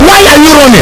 Play Why are you running earrape soundboard button | Soundboardly
why-are-you-running-earrape.mp3